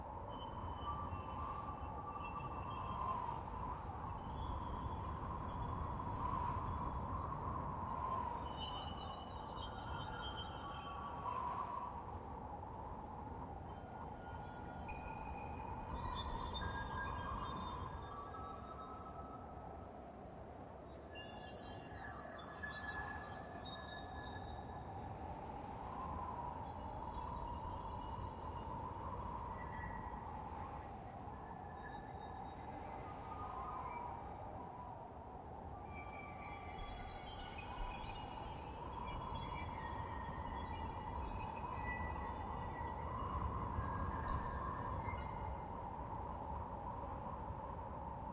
sfx-pm-hub-amb-02.ogg